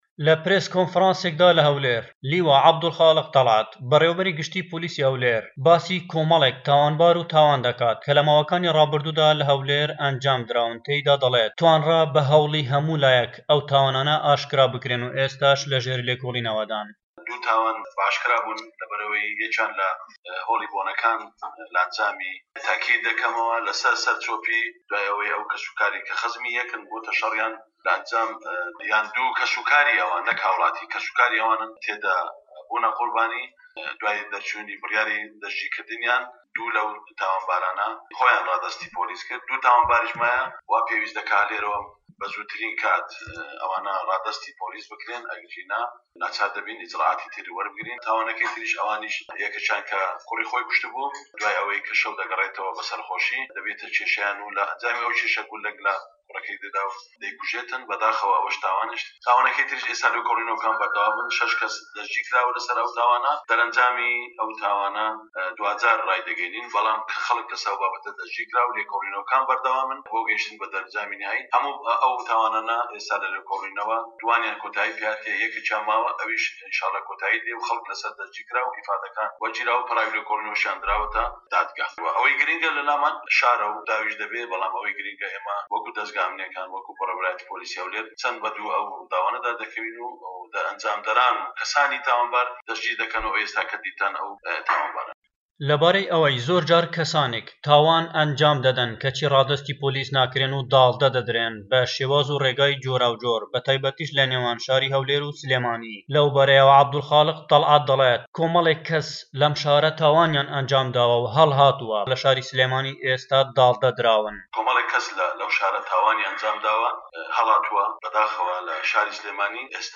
لە پرێس کۆنفرانسێکدا لیوا عەبدولخالق تەلعەت بەڕێوەبەری گشتی پۆلیسی هەولێر باسی کۆمەلێک تاوانبار و تاوان دەکات کە لە ماوەکانی رابردوودا لە هەولێر ئەنجامدراون، دەڵێت توانرا بە هەوڵی هەموو لایەک ئەو تاوانانە ئاشکرا بکرێن و ئێستا لە ژێر لێکولینەوەدان.
ڕاپۆرتی